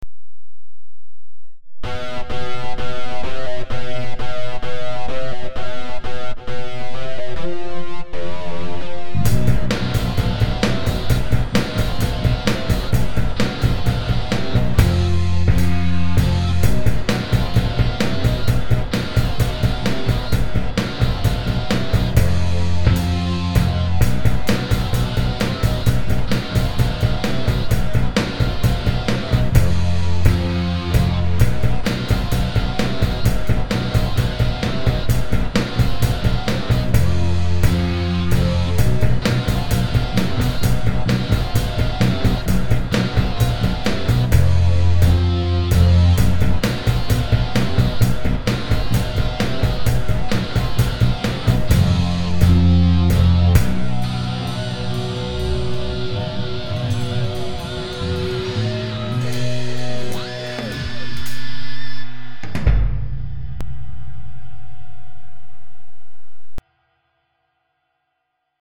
Tonalidade: si locrio; Compás 4/4